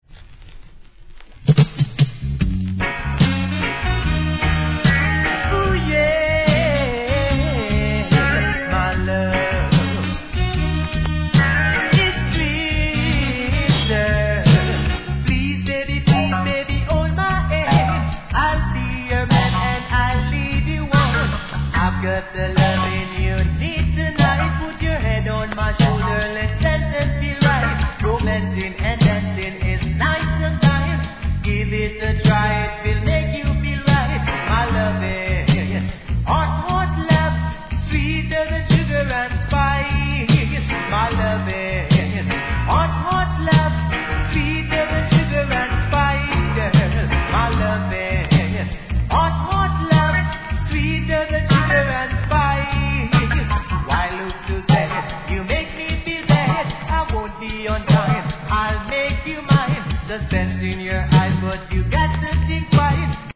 REGGAE
GOODヴォーカル